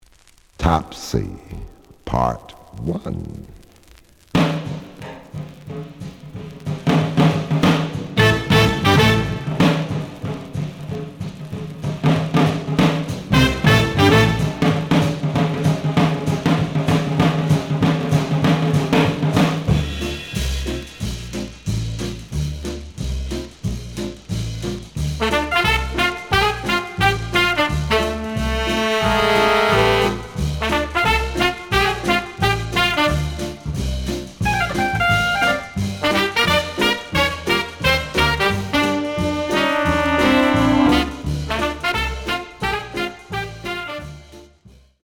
The audio sample is recorded from the actual item.
●Format: 7 inch
●Genre: Big Band